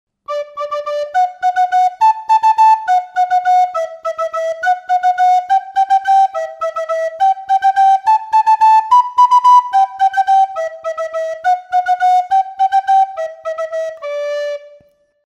Picado simple
Pito Galego